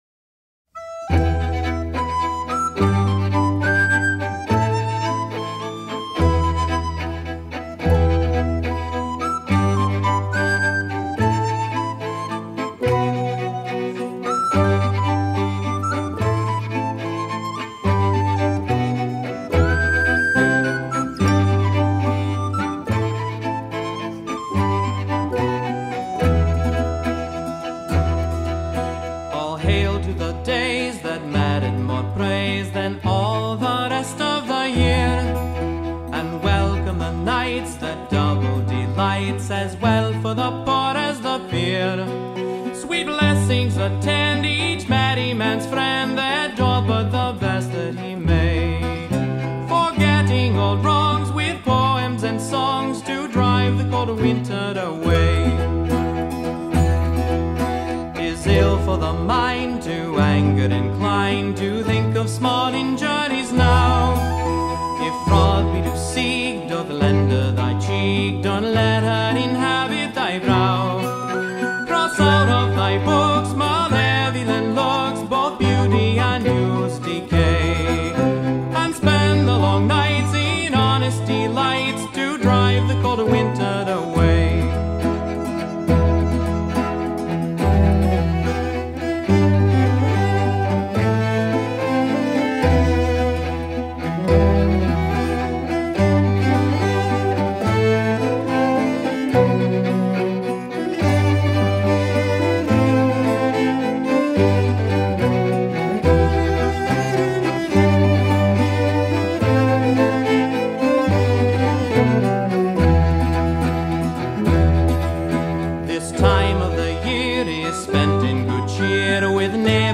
To Drive the Cold Winter Away es un villancico inglés de estilo secular que parece remontarse al Renacimiento.